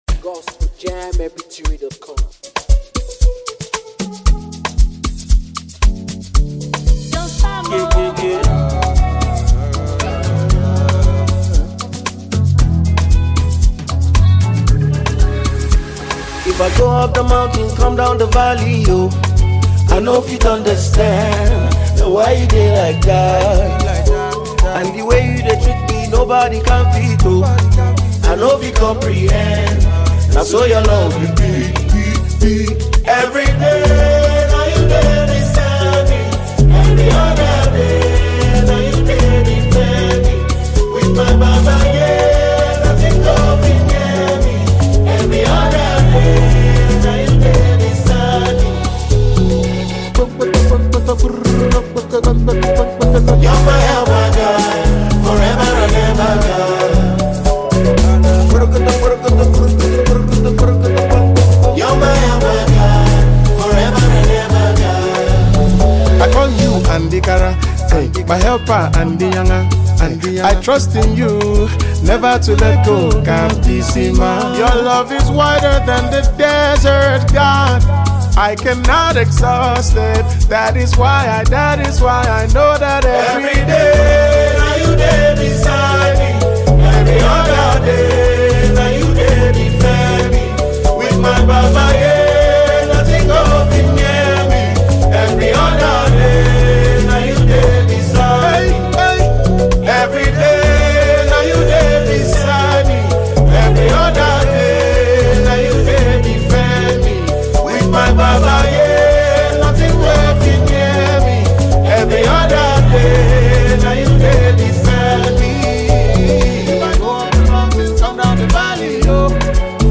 Renowned Nigerian Gospel Musician